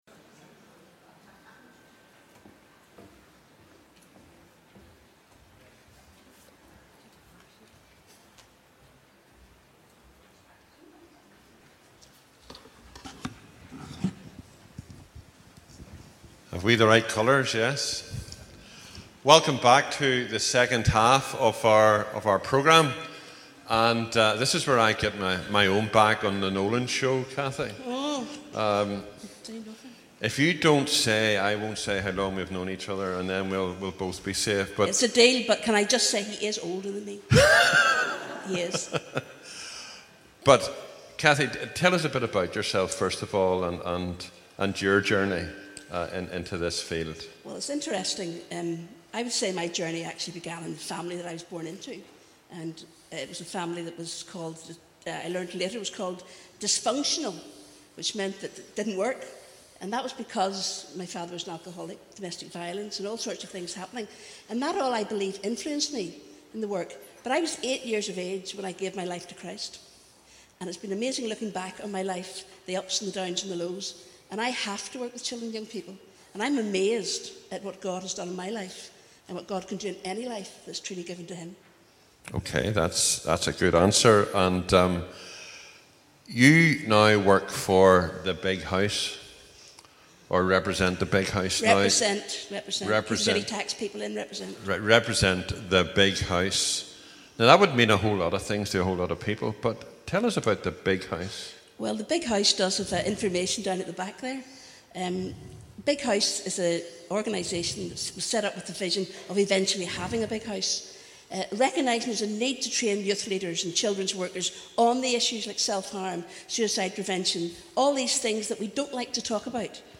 TC2014 Keynote Address
On the morning of Saturday 12th April 300 Designated Persons, Ministers, youth leaders and parents all gathered at assembly buildings to enjoy fantastic cupcakes together but more importantly than that, to consider some difficult issues and learn from experts across various fi...